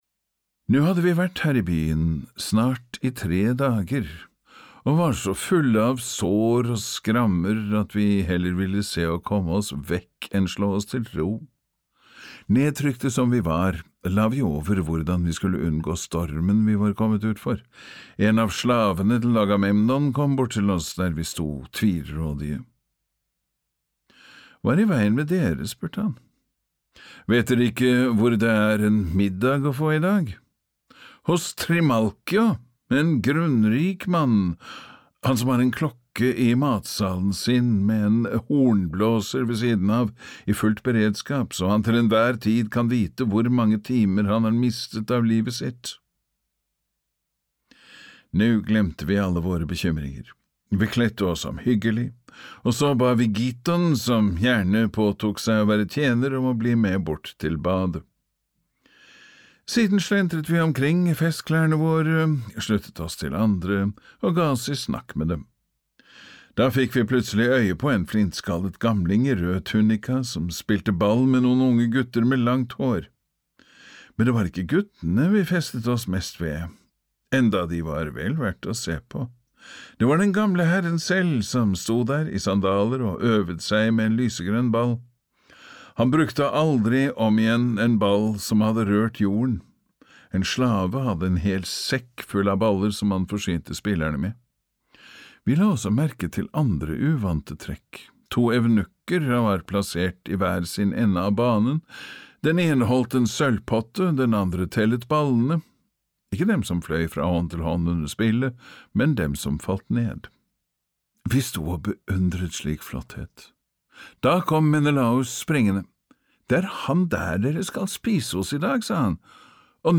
Trimalchios middag (lydbok) av Petronius Arbiter